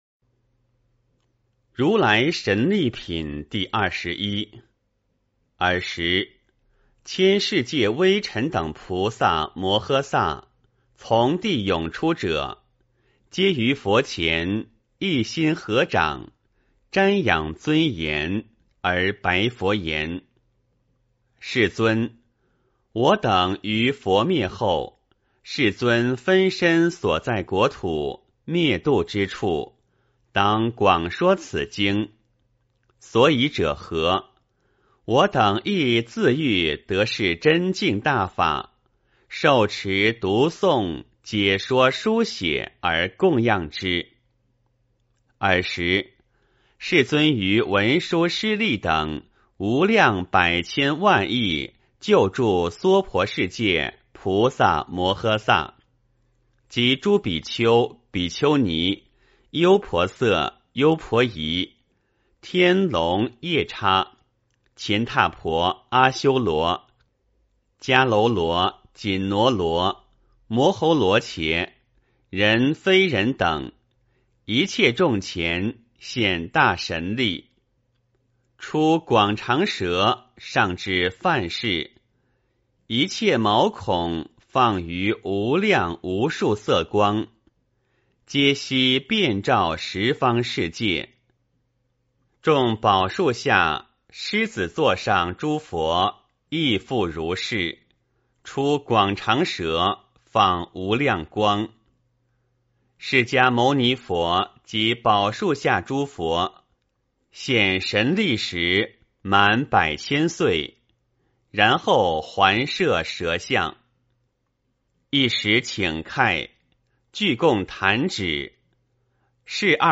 法华经-如来神力品第二十一 - 诵经 - 云佛论坛